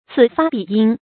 此發彼應 注音： ㄘㄧˇ ㄈㄚ ㄅㄧˇ ㄧㄥˋ 讀音讀法： 意思解釋： 這里發動，那里響應。